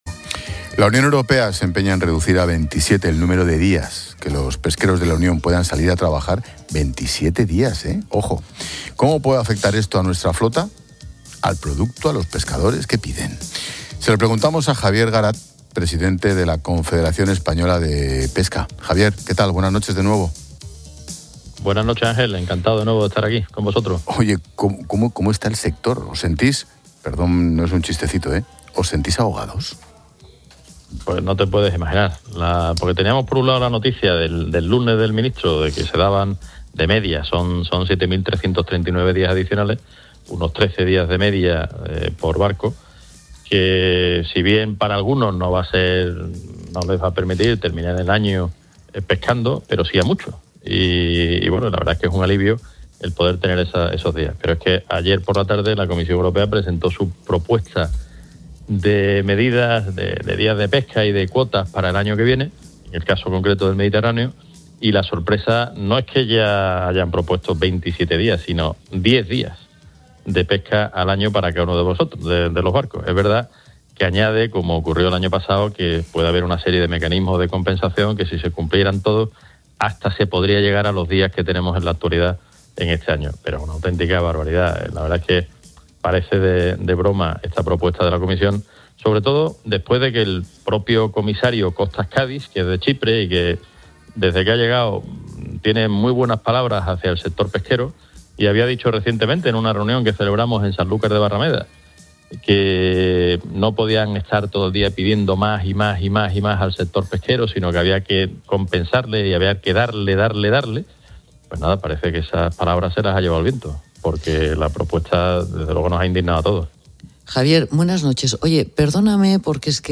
Ángel Expósito y Pilar García de la Granja analizan la crisis con el pescado en España antes de la Navidad